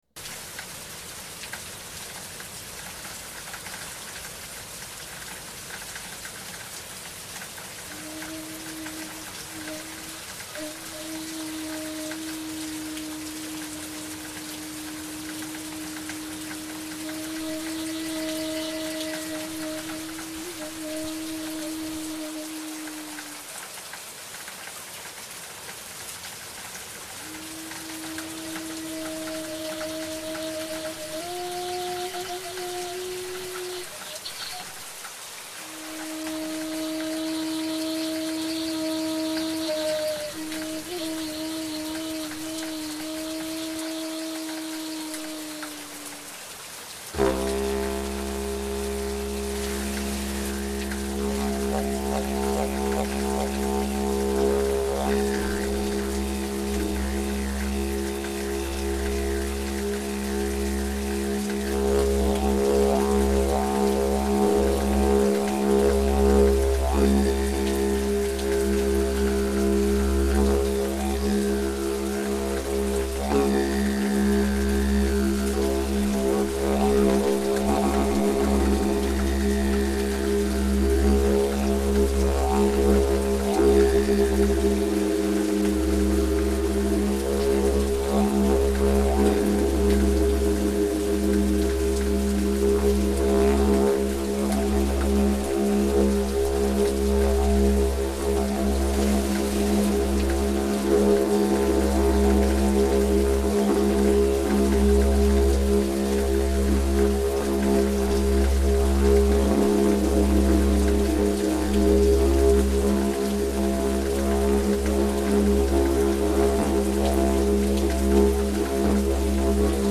Voice lost in the wind and rain, sitting under Illtyd’s tree at Llanilltyd, Ilston, this morning. So today just didj and flute and nature